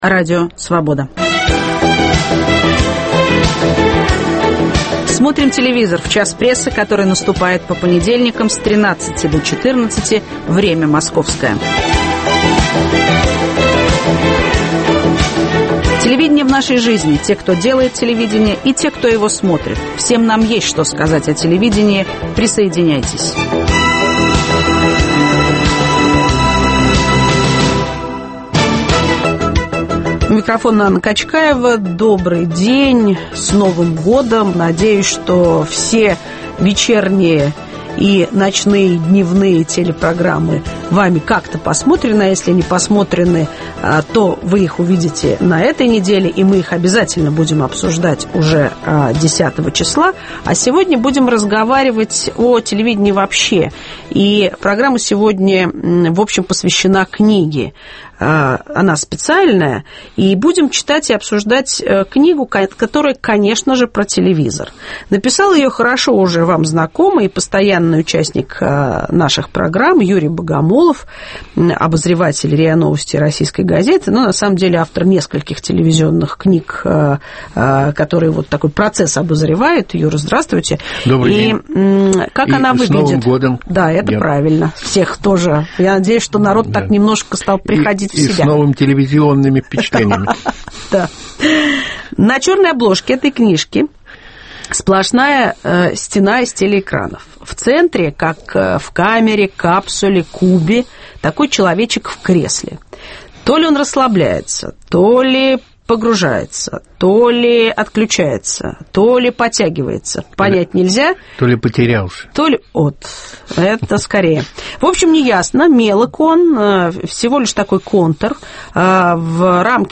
Телевидение в нашей жизни. О людях, программах и телевизионных событиях каждый понедельник Анна Качкаева разговаривает со слушателями и экспертами.